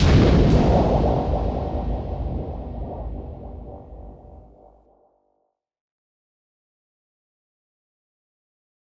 Processed Hits 20.wav